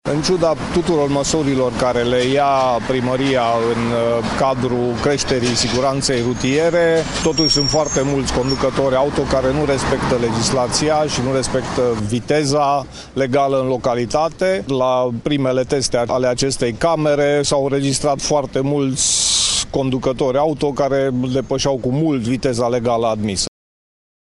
Viceprimarul Brasovului, Barabas Laszlo: